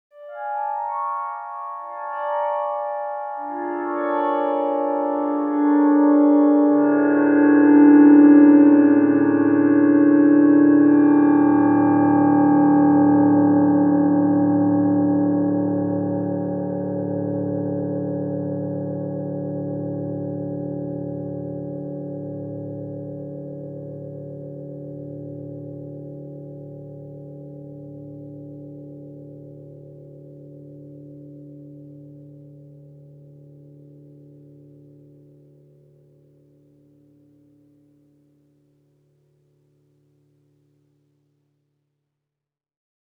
5 files, consisting of various versions of the piano arpeggio, were convolved with themselves and/or other files in various combinations using SoundHack to produce various hybrids, each normalized with high frequency boost, and later EQ'd with fades added; granular time stretching using Chris Rolfe's MacPod was also used.
I. Convolved with itself
Piano Arp3D